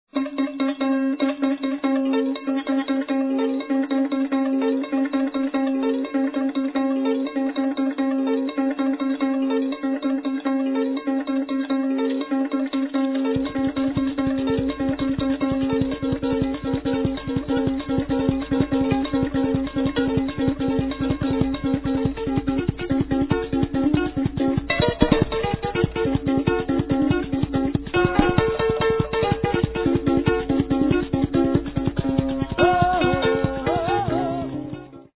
traditional Malagasy songs